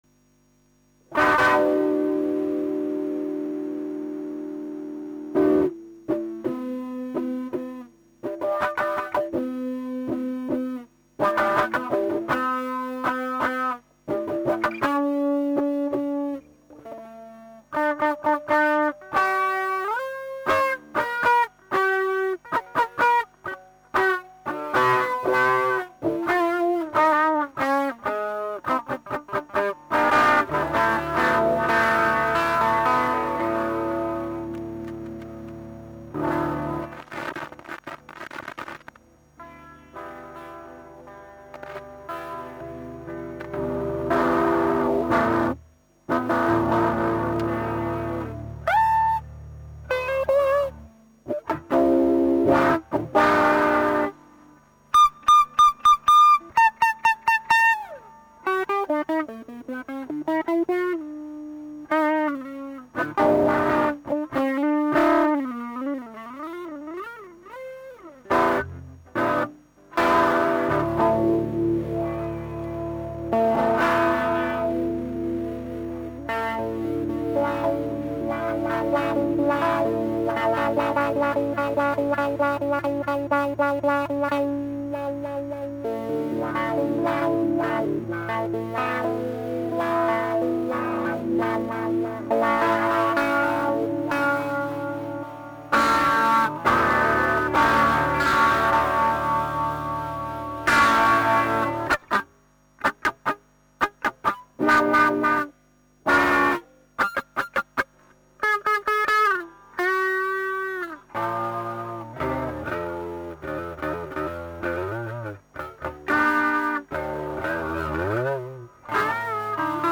ジャリガリ